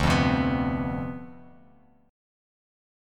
Dm13 chord